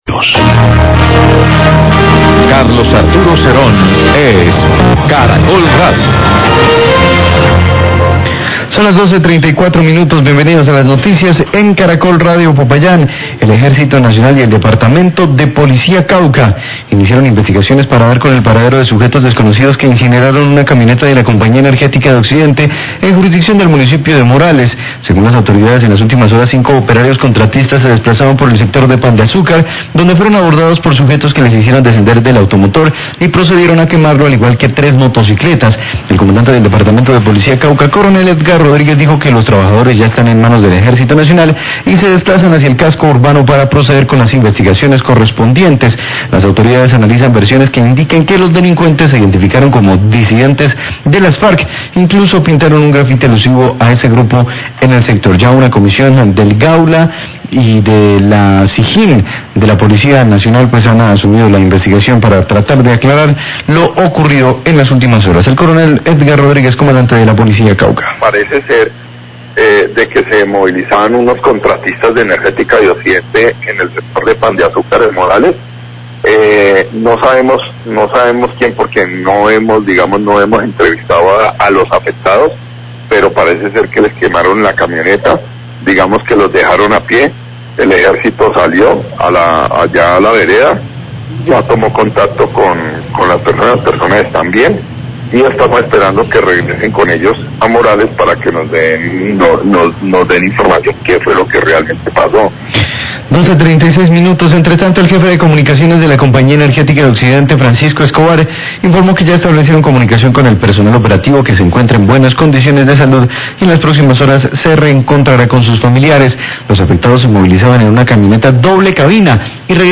Radio
Autoridades militares investigan acción de una disidencia de las Farc quienes quemaron un vehículo de un contratista de la Compañía Energética y tres motocicletas en el sector rural de Morales. Habla el coronel Edgar Rodriguez, comandante de la Policía Cauca.